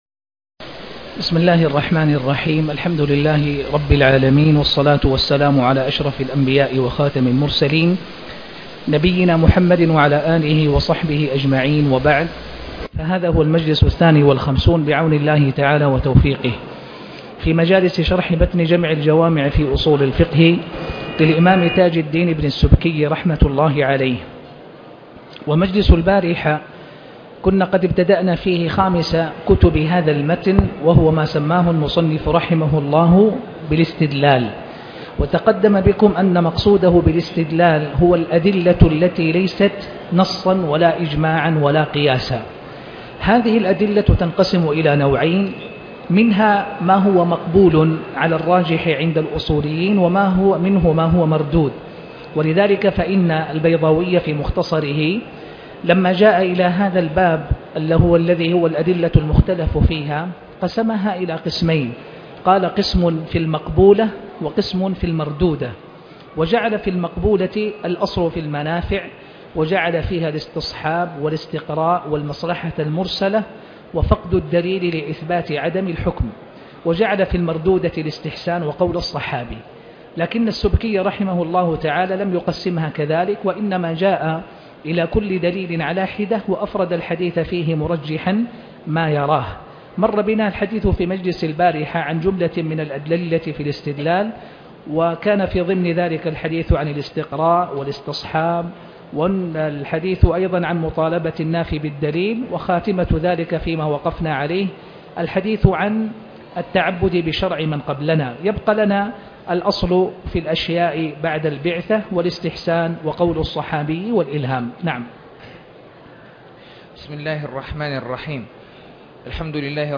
شرح جمع الجوامع الدرس 52 - الاستدلال (2) - في 4-5-1438هـ